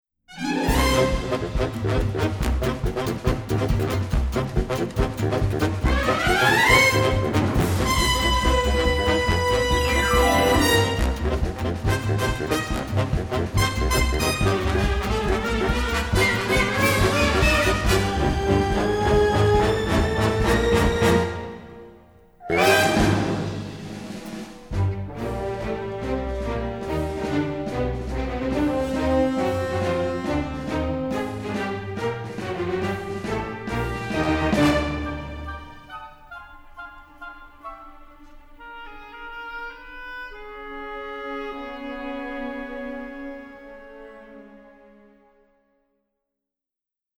charming orchestral score